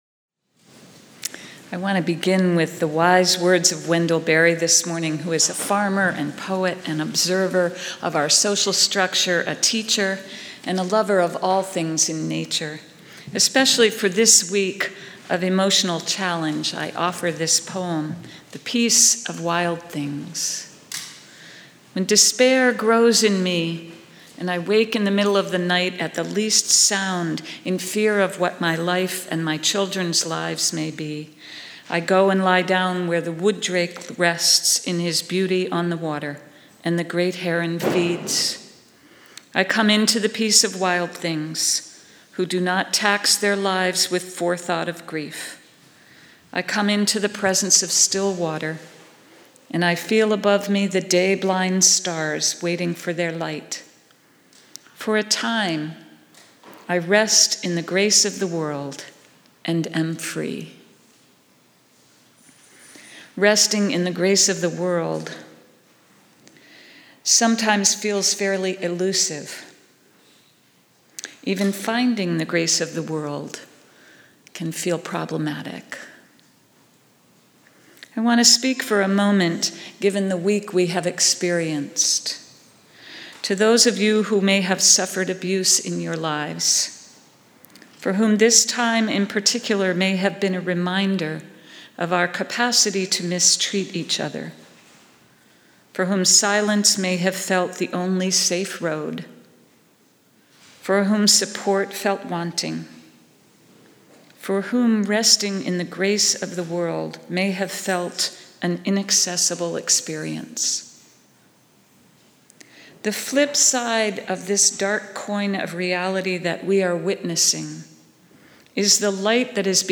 All God’s Critters – A Service of Blessing